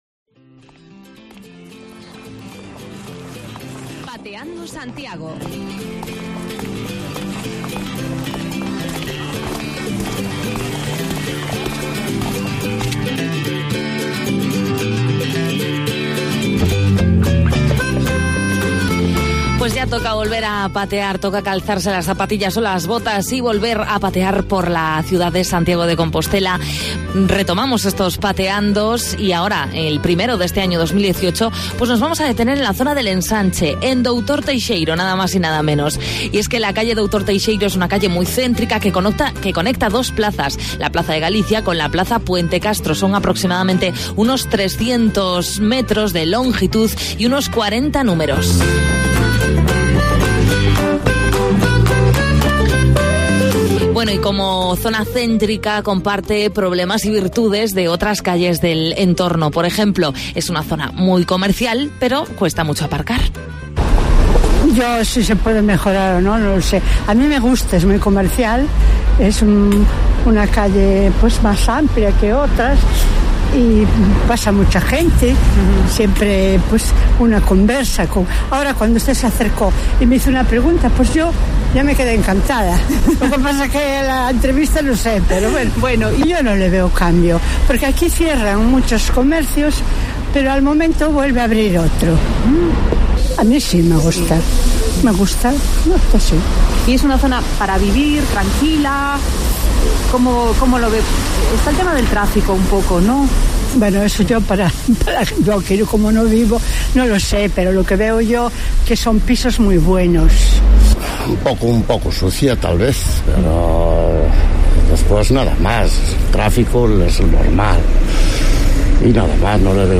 Los micrófonos de Cope+Santiago volvieron este lunes a bajar a la calle, a una de las arterias de la zona nueva: Doutor Teixeiro.